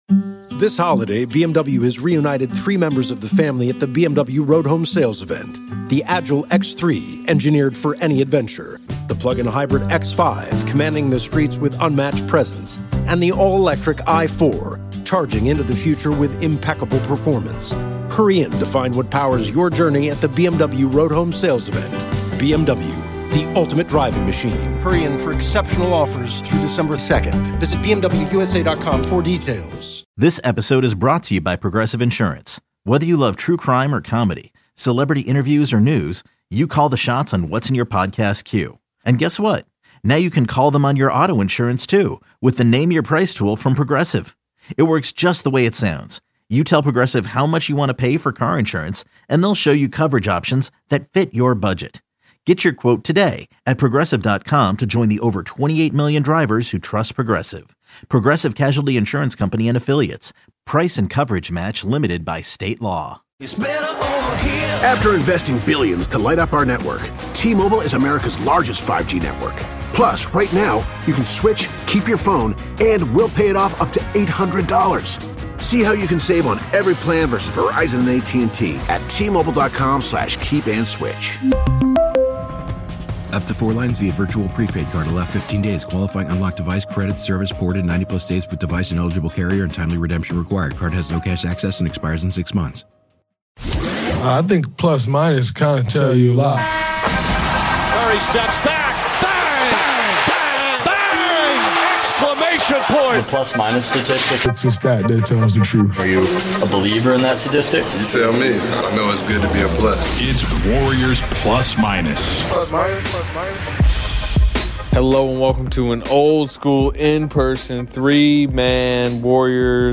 come at you live in person after the Warriors' 120-117 win over the Mavericks in Klay Thomoson's return to Chase Center.